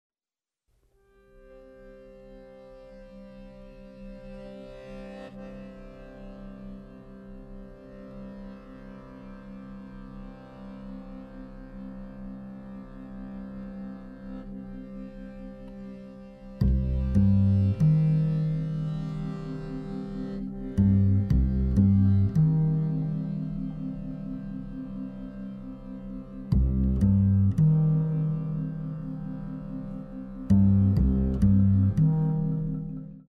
congas, marimba, bodhran;
bass clarinet, alto sax & soprano sax